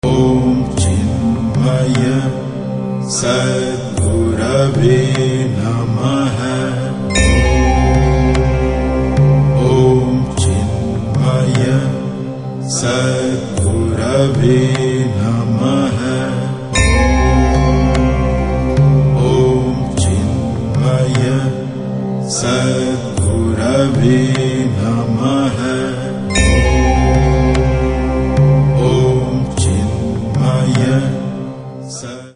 continuous chants with music